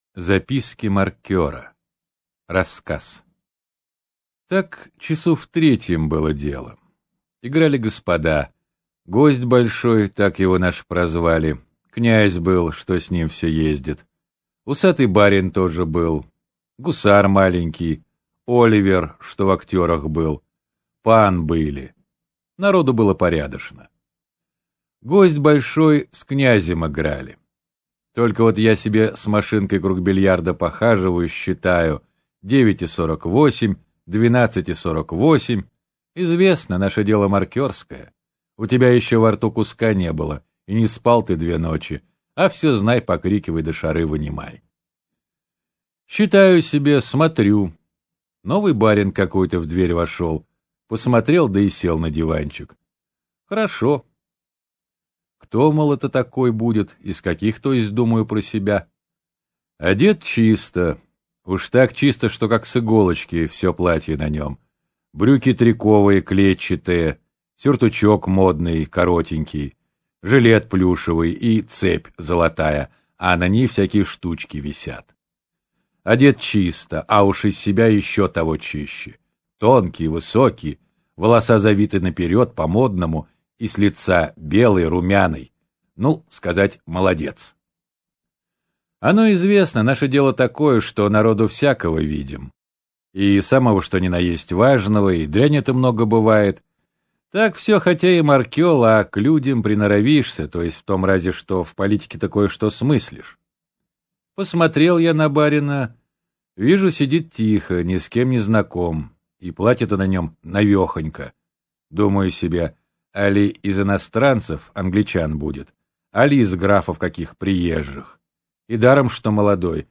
Аудиокнига После бала. Альберт. Записки маркёра | Библиотека аудиокниг